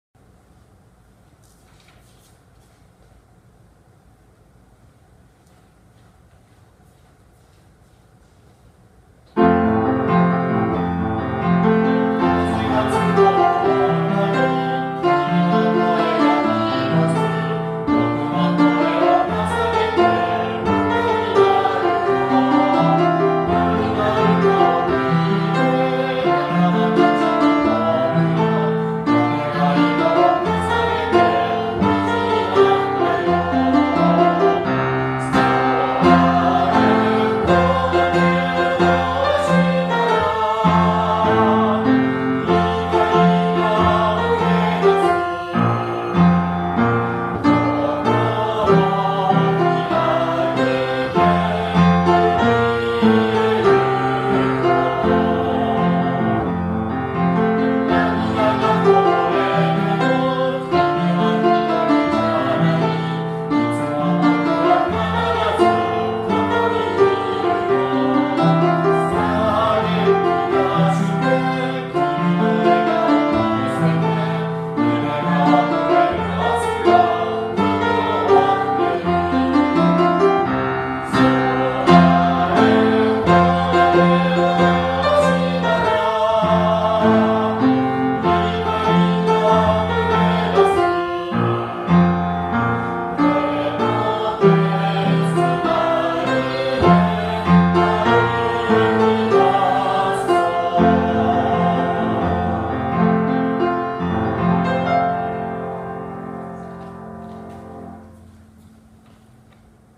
日々の教育を明日に繋げ、卒業後に自立した豊な生活を送れるようにすること ＜目指す生徒の姿＞ 主体的に学び、命の大切さや自己を理解し、人や社会とつながりながらその能力や可能性を最大限に発揮して生活する姿 校章 校歌 校歌歌詞[PDF：96KB] 校歌（歌あり）[MP3：1.68MB]